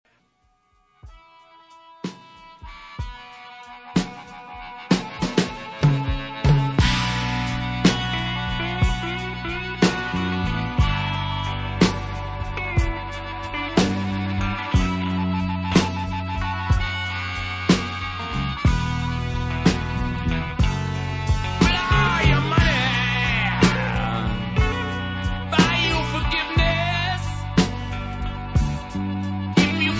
• rock
• registrazione sonora di musica